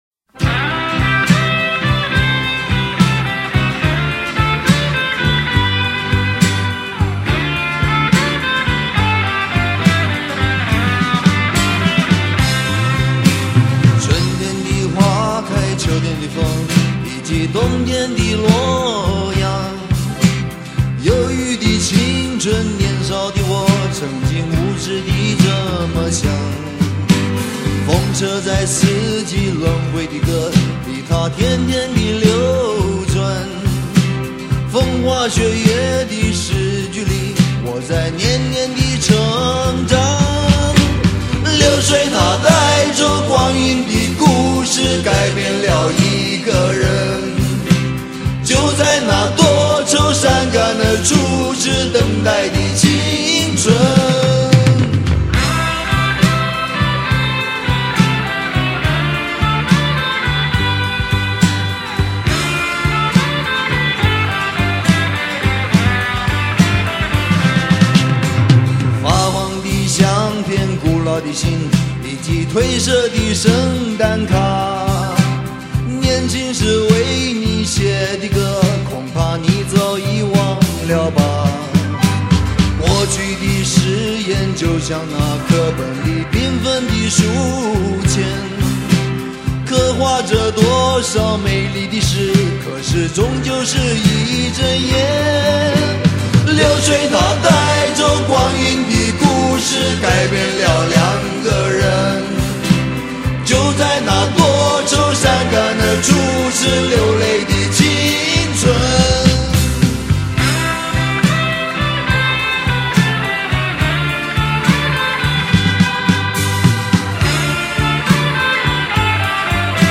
风格: 流行